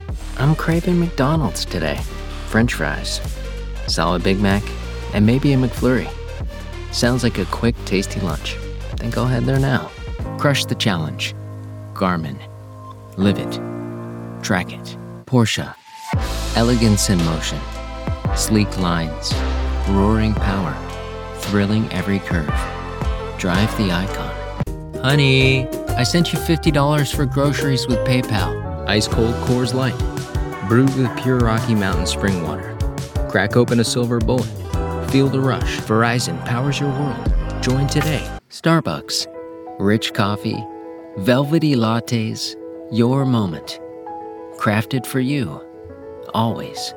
Commercial
English - USA and Canada